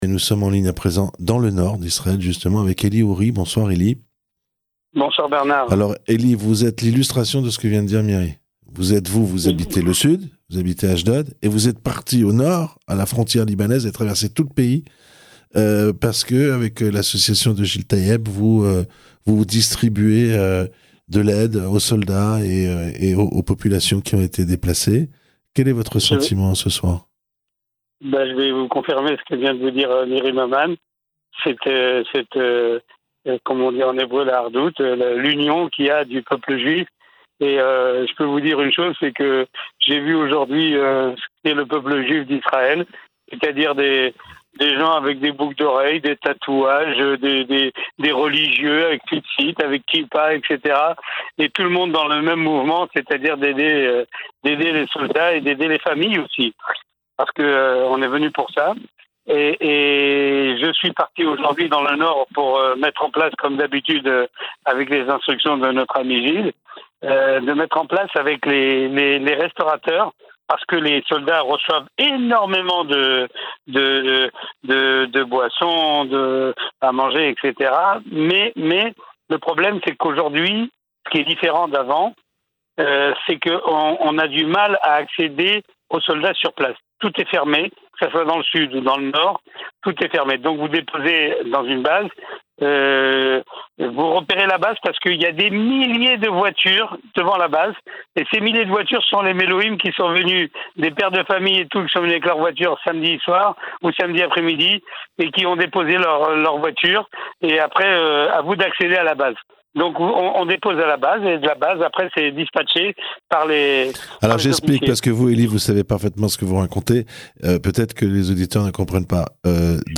en direct de la frontière israélo-libanaise. Il explique l'énorme mobilisation de la population israélienne pour ses soldats après le terrible massacre perpétré par le Hamas